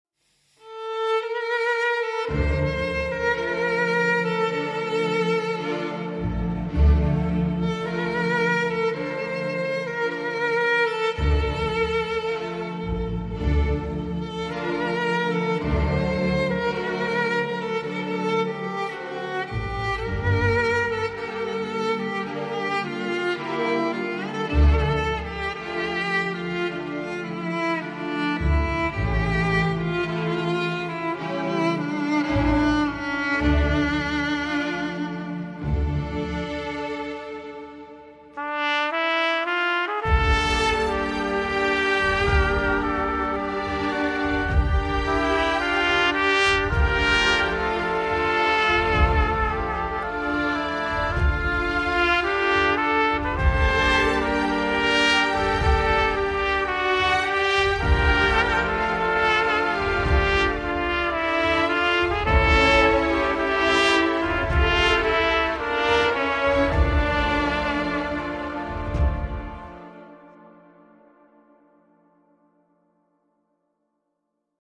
زیبا و حماسی خدا اجرتان دهد